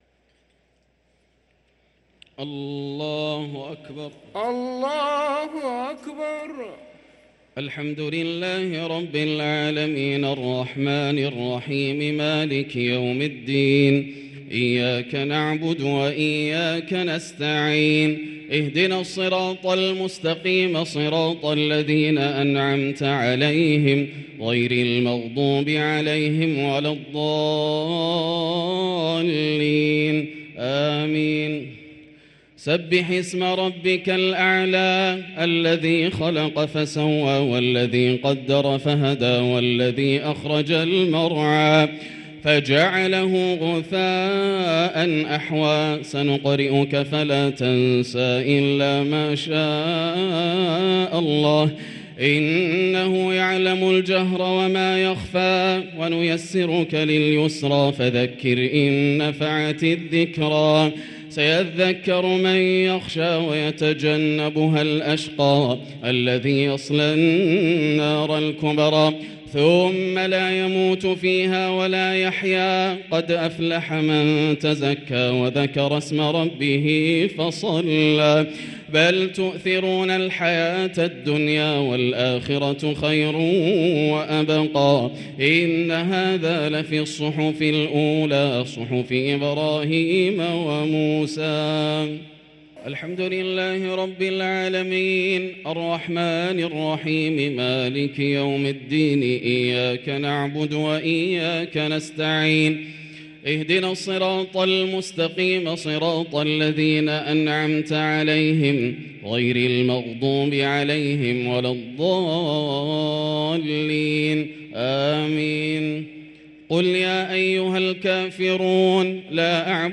صلاة التراويح ليلة 12 رمضان 1444 للقارئ ياسر الدوسري - التسليمتان الأخيرتان صلاة التراويح